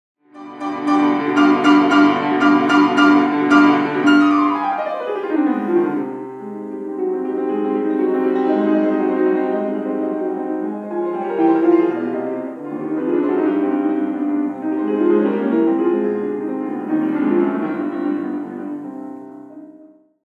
(Again, sorry for the sound, it’s just a little mic in my practicing studio. But in two weeks I will record for real…)
Well, this movement is just a whirlwind: